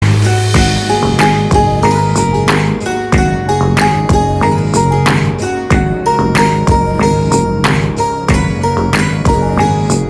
Ringtones Category: Instrumental